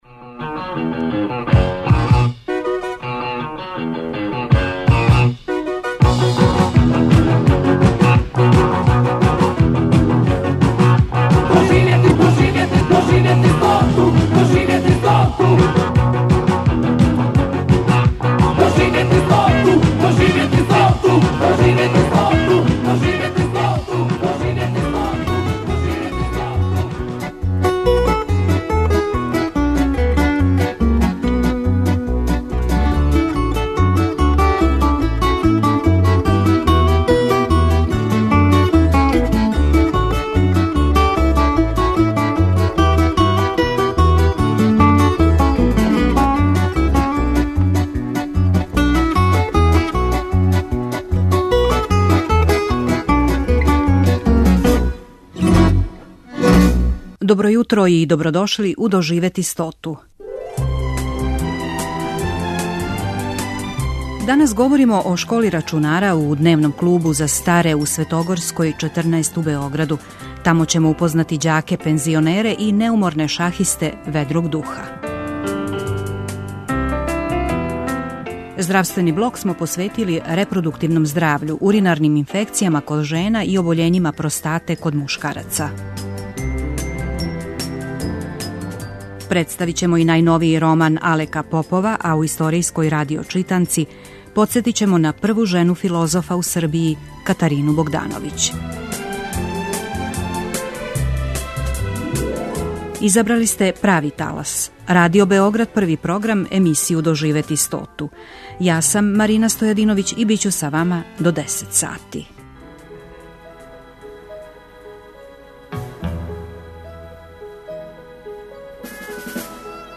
Емисија "Доживети стоту" Првог програма Радио Београда доноси интервјуе и репортаже посвећене старијој популацији.